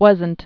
(wŭzənt, wŏz-)